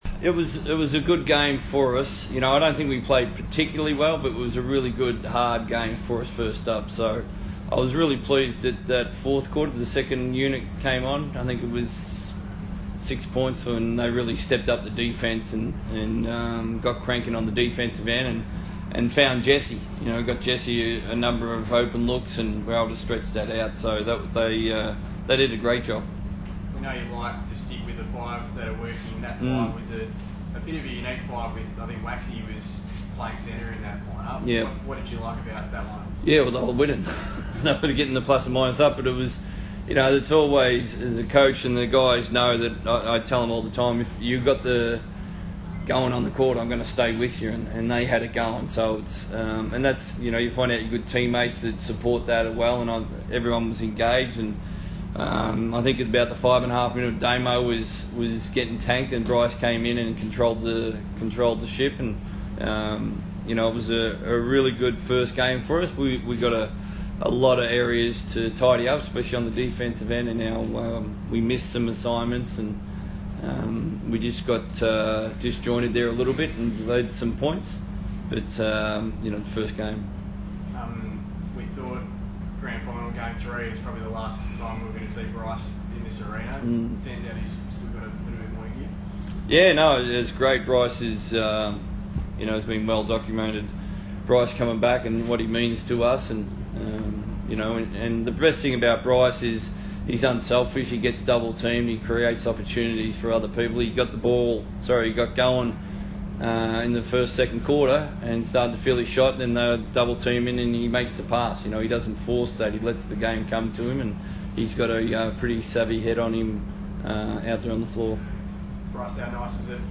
Post Game Press Conference